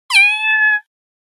猫の鳴き声-05は、子猫が鳴く特徴的な「ニャー」という鳴き声を表現したものです。
猫の鳴き声-05：子猫のニャーと鳴く声 着信音
この鳴き声は、子猫が注意を引いたり、餌を求めたり、不安を伝えたりする際によく使われます。まず、耳に届くのはかわいらしい高い声の「ニャー」という鳴き声です。